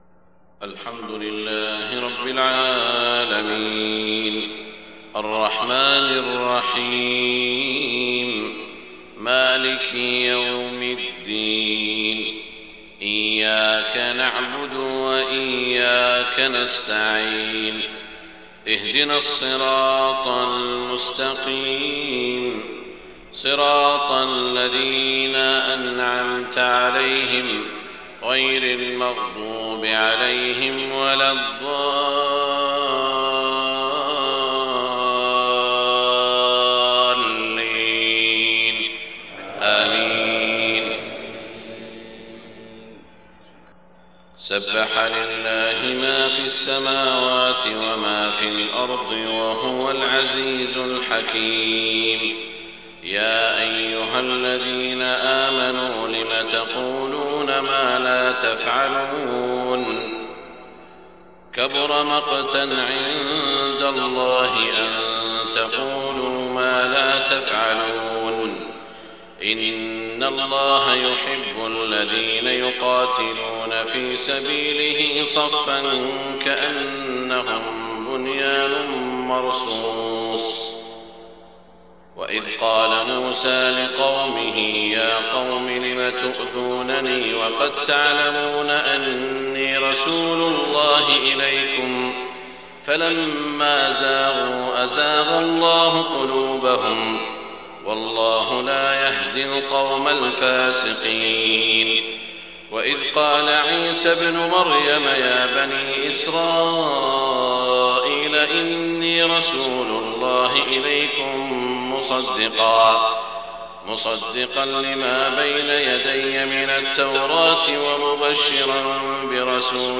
صلاة الفجر 28 ربيع الأول 1430هـ سورتي الصف و الجمعة > 1430 🕋 > الفروض - تلاوات الحرمين